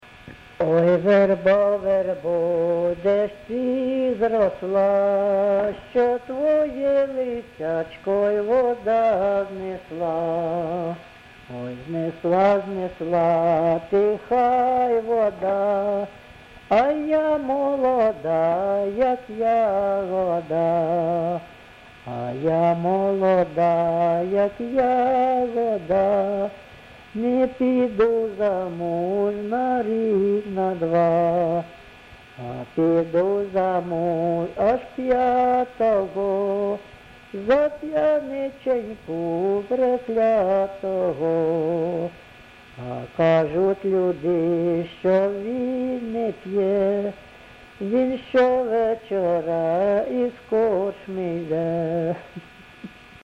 ЖанрПісні з особистого та родинного життя
Місце записум. Антрацит, Ровеньківський район, Луганська обл., Україна, Слобожанщина